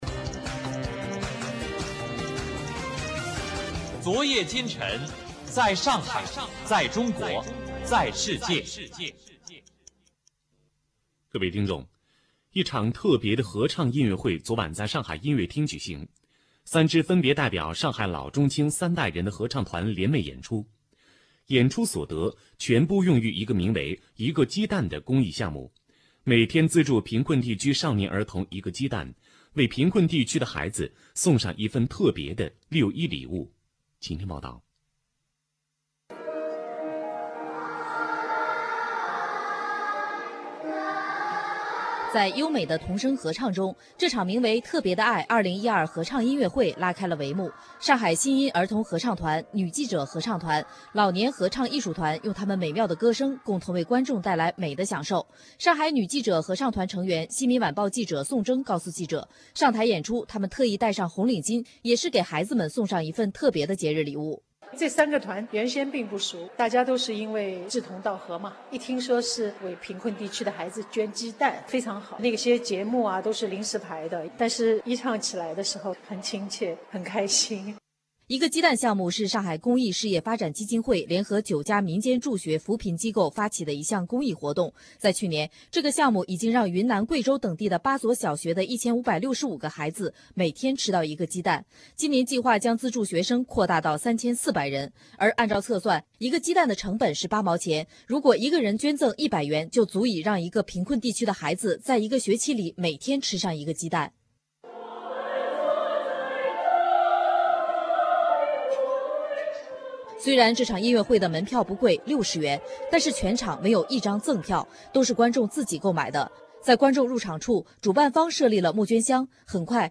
以下是来自东广新闻台的报导音频：